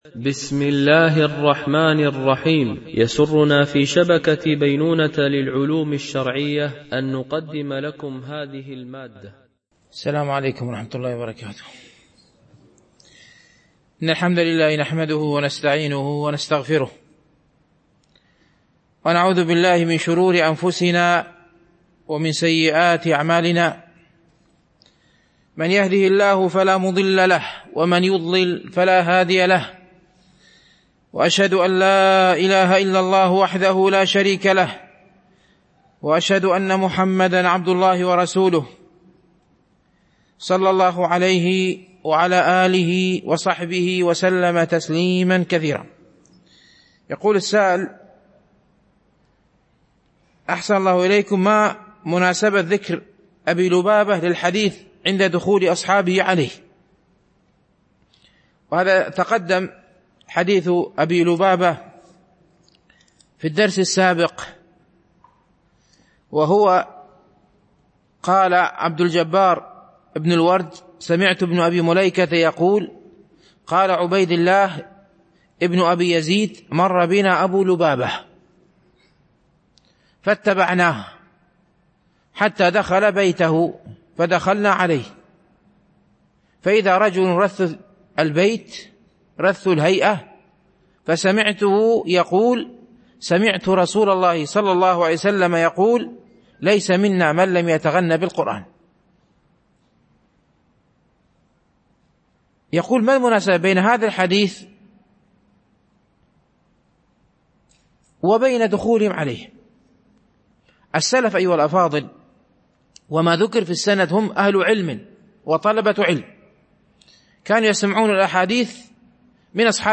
شرح رياض الصالحين – الدرس 263 ( الحديث 1016 – 1019 )